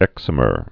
(ĕksə-mər)